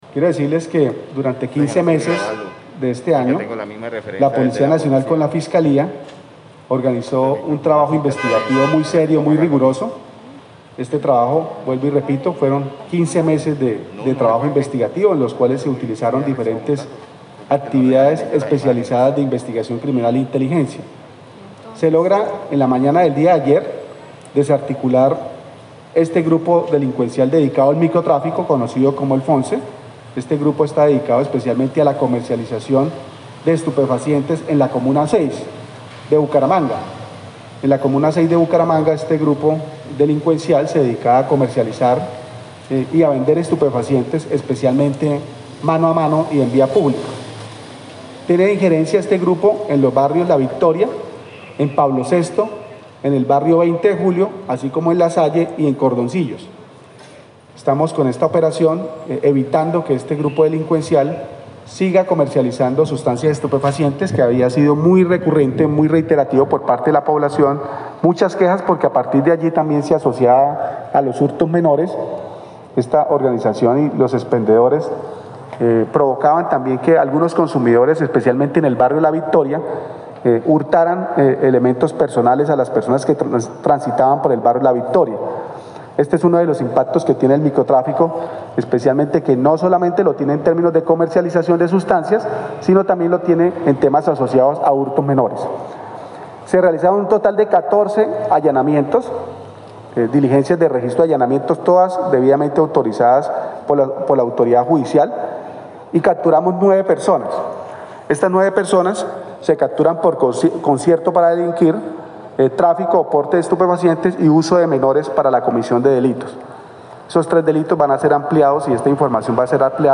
Brigadier-general-Luis-Garcia-comandante-de-la-Policia-Metropolitana-de-Bucaramanga-1.mp3